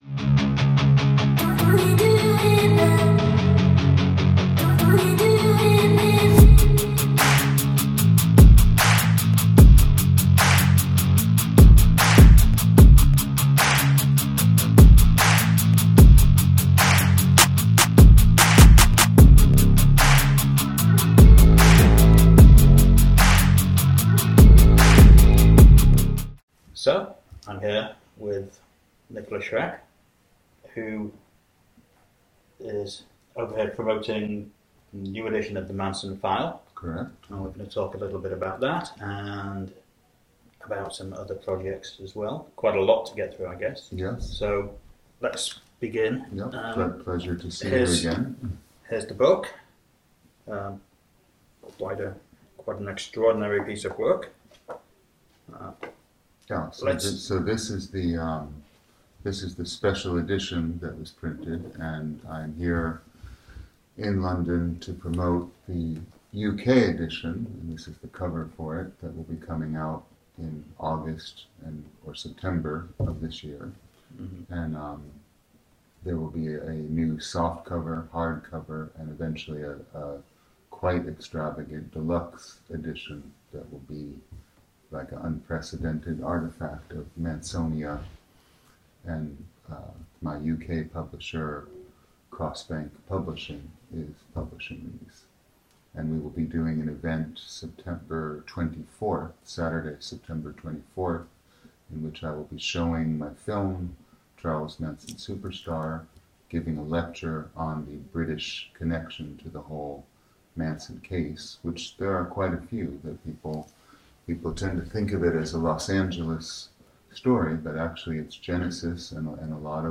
Nikolas-Schreck-Interview.m4a